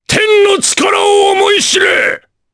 Kaulah-Vox_Skill7_jp.wav